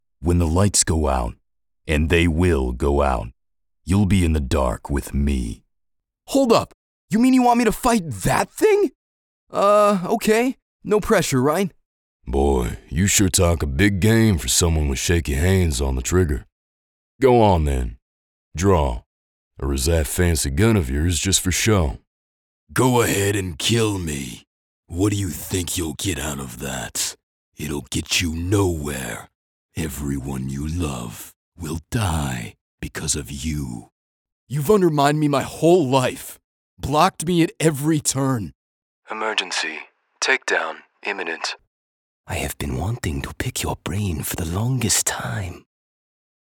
Voiceover Demos
Character Sample
Broadcast-Quality Studio